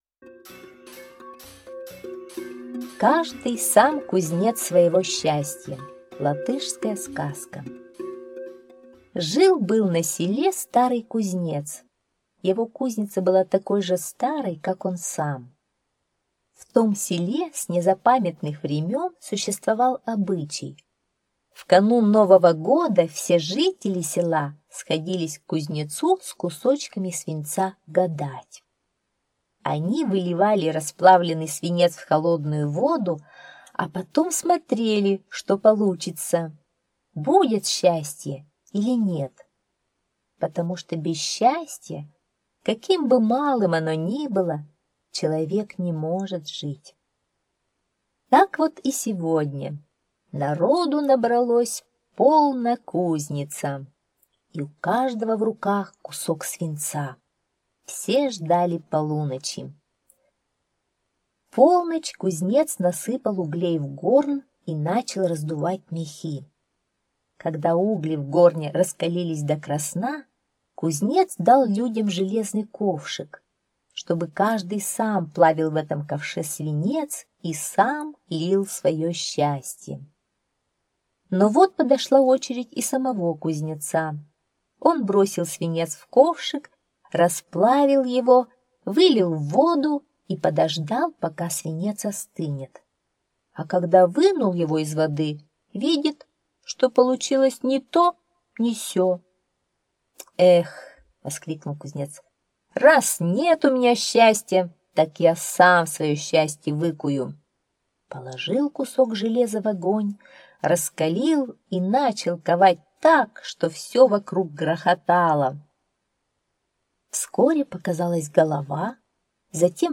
Каждый сам кузнец своего счастья – латышская аудиосказка